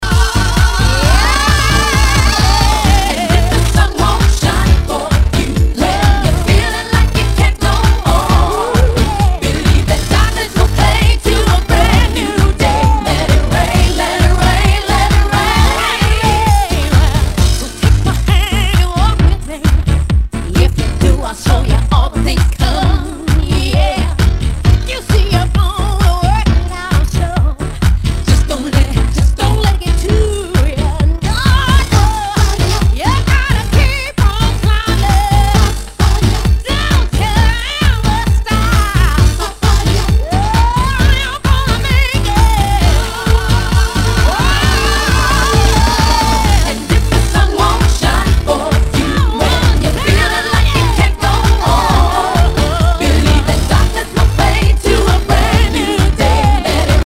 HOUSE/TECHNO/ELECTRO
ナイス！ヴォーカル・ハウス！